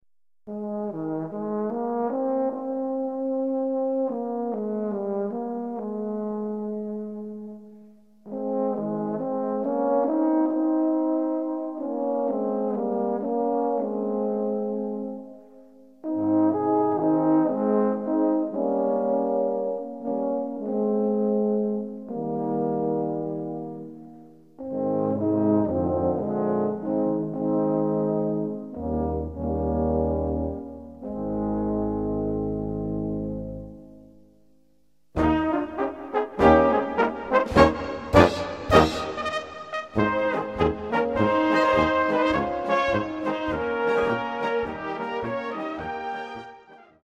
Gattung: Böhmische Polka
Besetzung: Blasorchester